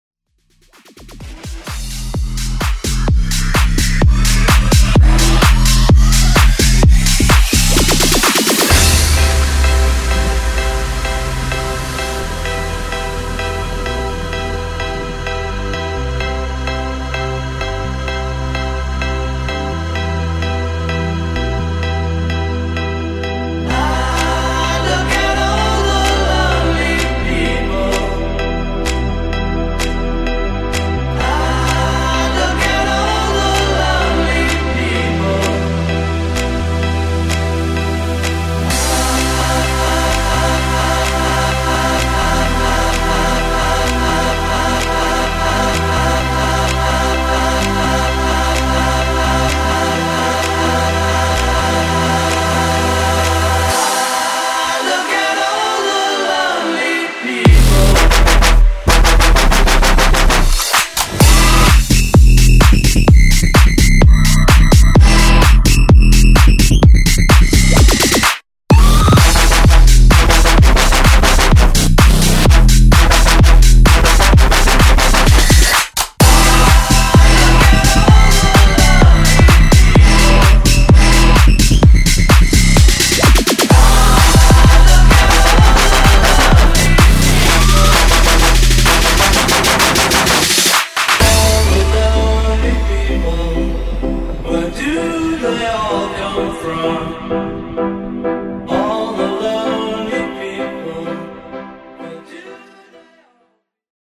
Genre: 70's
Clean BPM: 124 Time